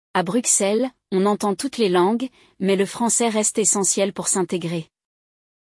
No episódio de hoje, você vai embarcar em uma conversa entre dois amigos que se reencontram em Bruxelas, cidade conhecida por sua multiculturalidade e por ser um dos centros políticos mais importantes da Europa.
Por isso, no episódio de hoje, você terá a oportunidade de escutar uma conversa real, com entonações naturais e expressões comuns no francês falado.